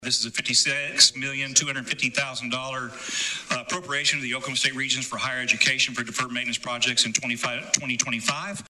CLICK HERE to listen to Senator Chuck Hall explain Senate Bill 1185.